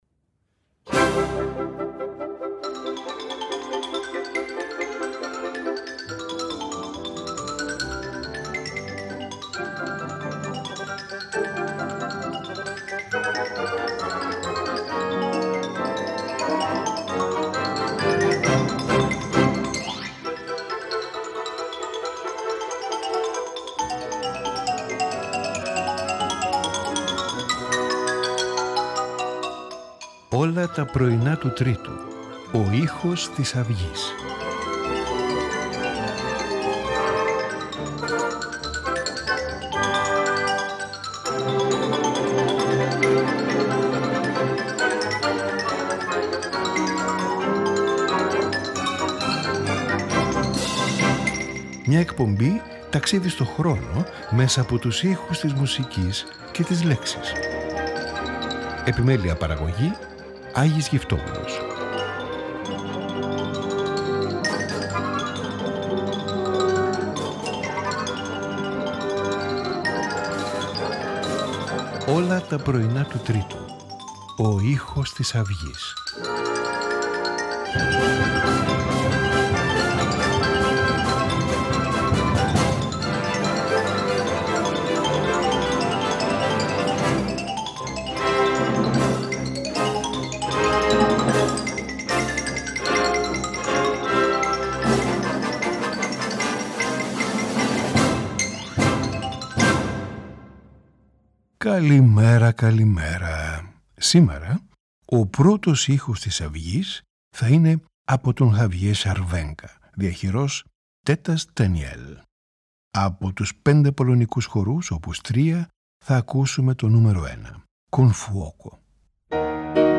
Sonata for violin and piano